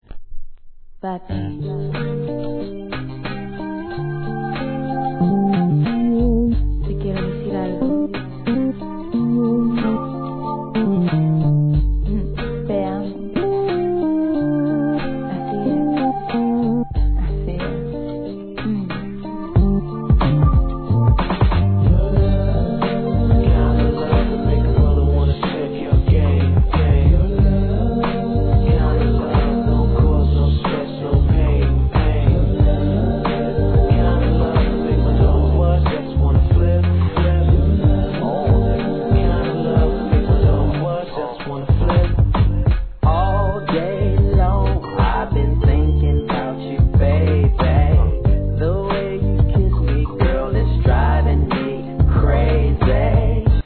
HIP HOP/R&B
哀愁漂うアコースティックのメロディーで歌い上げるGOOD R&B!!